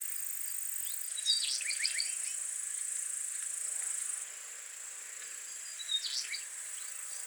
Weidenmeise Parus montanus Willow Tit
Widdumer Weiher
13.08.2012 8 s abweichende Rufe